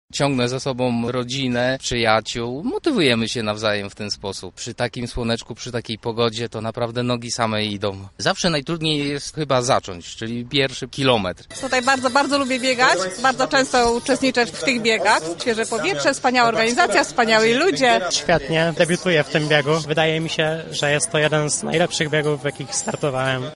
O wrażeniach opowiadają sami uczestnicy:
sonda-6.mp3